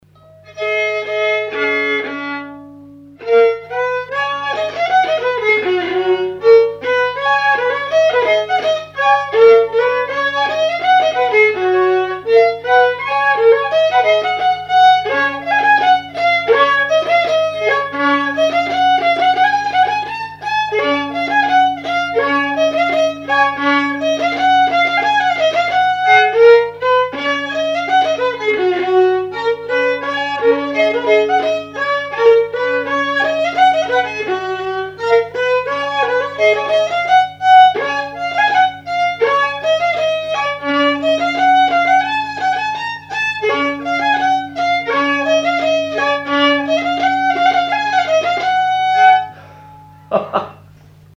Mémoires et Patrimoines vivants - RaddO est une base de données d'archives iconographiques et sonores.
danse : mazurka
répertoire d'air pour la danse au violon et à l'accordéon
Pièce musicale inédite